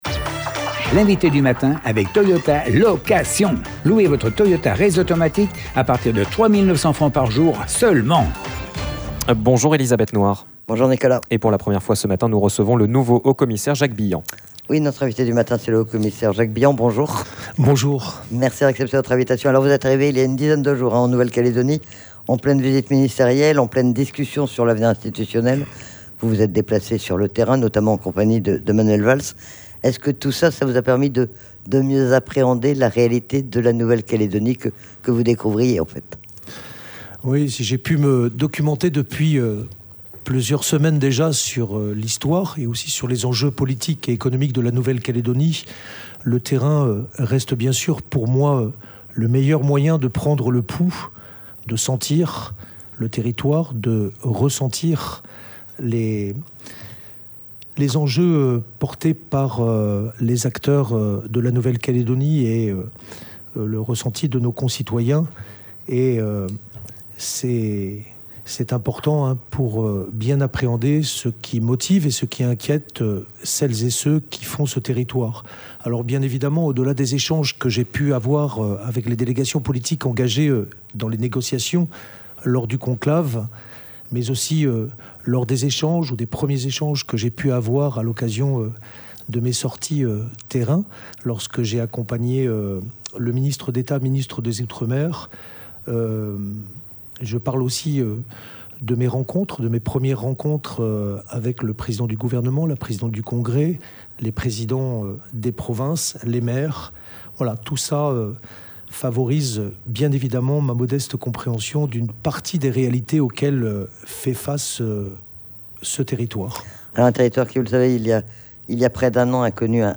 C'est le nouveau haut-commissaire, Jacques Billant, qui était notre invité du matin. Nous l'avons interrogé sur ses premières impressions sur la Nouvelle-Calédonie, sur la situation du territoire, un an après le début des violences insurrectionnelles et su ses priorités, une dizaine de jours après sa prise de fonction.